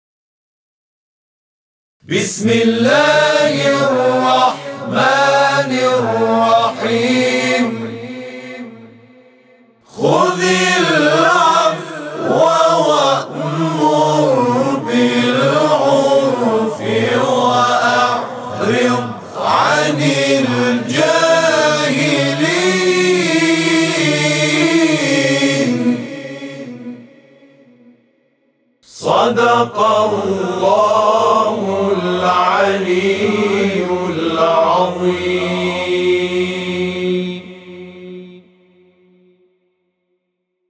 صوت همخوانی آیه 199 سوره اعراف از سوی گروه تواشیح «محمد رسول‌الله(ص)»